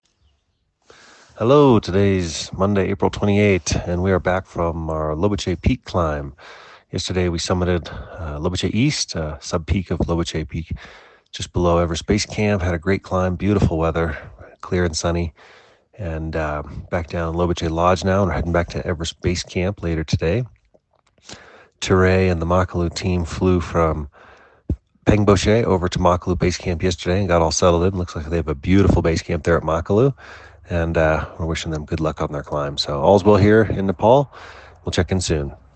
checks in with this dispatch from Lobuche village: